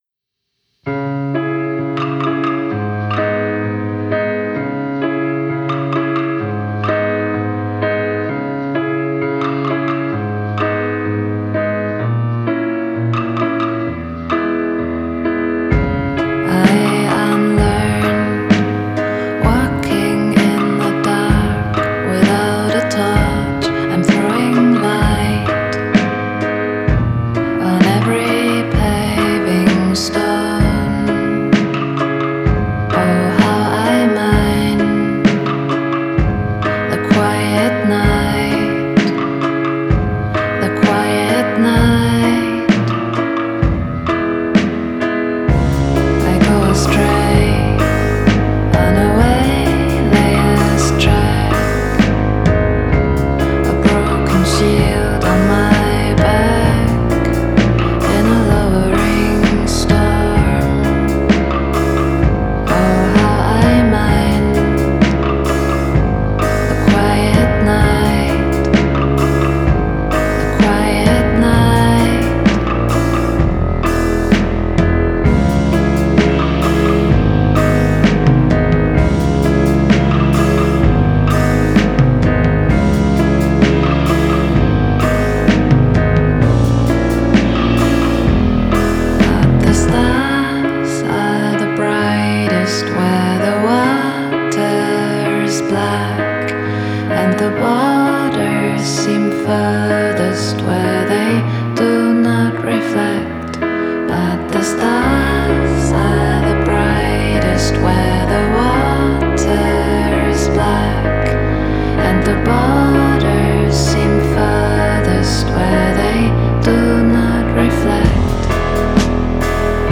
Genre: Dream Pop, Indie Folk, Singer-Songwriter